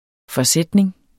Udtale [ fʌˈsεdneŋ ]